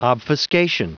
Prononciation du mot obfuscation en anglais (fichier audio)